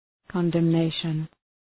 Προφορά
{,kɒndem’neıʃən}